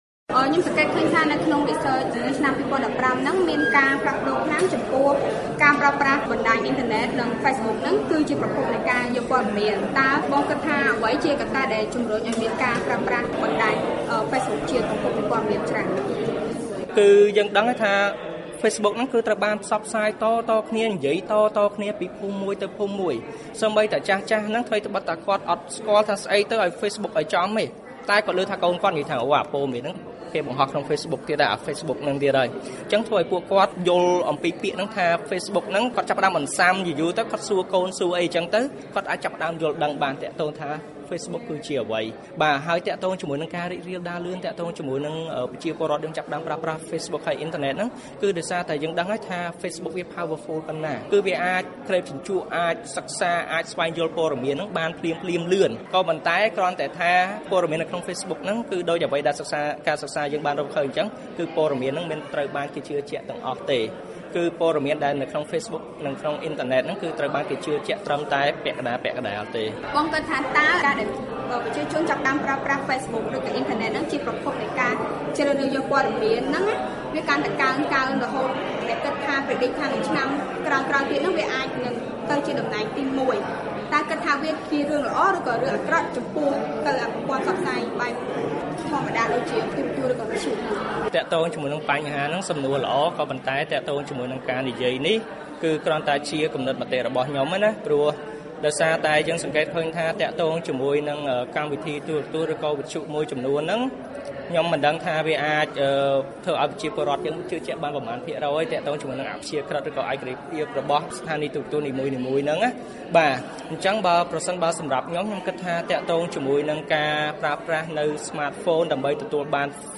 បទសម្ភាសន៍